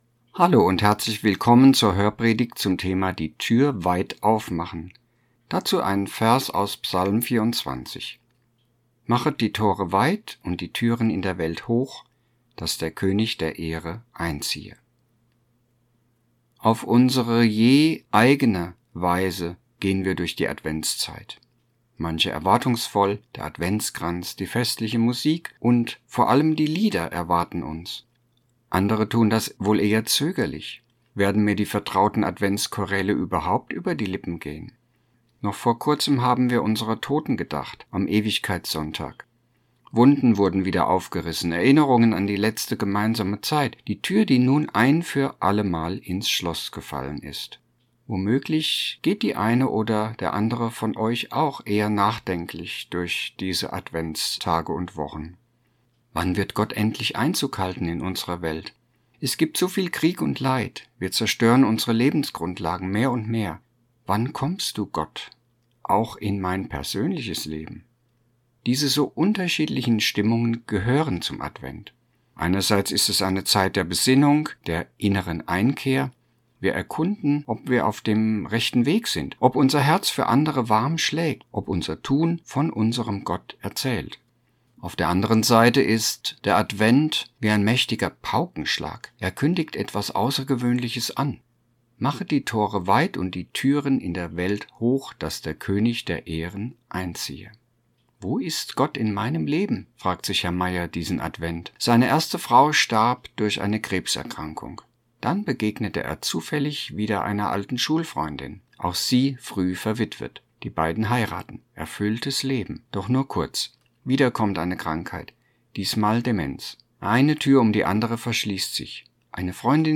Hörpredigt-ZV-Dezember_und_Advent_2025.mp3